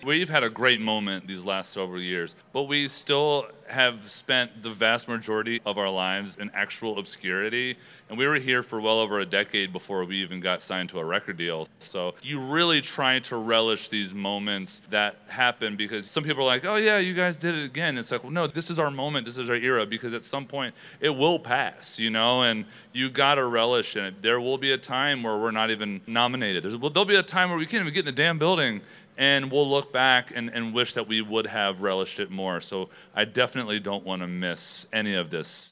Audio / Brothers Osborne's John Osborne talks about relishing their success at this point in their lives.